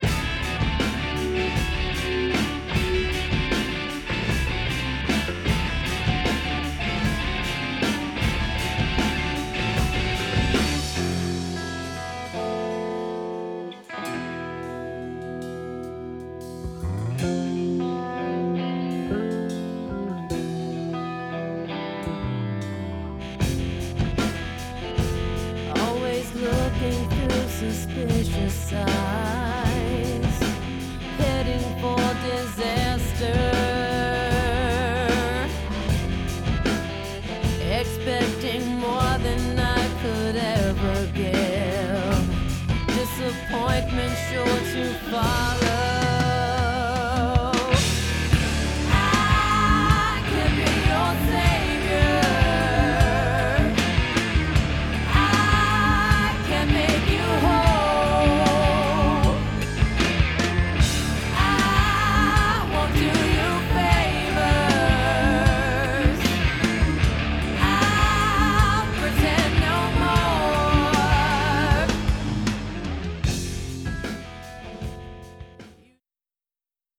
Here they are (note the file are raw, unmastered WAV files so I have links to them as opposed to using the player):
Here’s the song with a stock power cord…
Personally, I can’t tell a difference between the two, and I used flat-response studio cans to evaluate the clips.